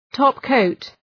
Προφορά
{‘tɒp,kəʋt}